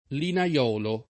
vai all'elenco alfabetico delle voci ingrandisci il carattere 100% rimpicciolisci il carattere stampa invia tramite posta elettronica codividi su Facebook linaiolo [ lina L0 lo ] (lett. linaiuolo [ lina LU0 lo ]) s. m.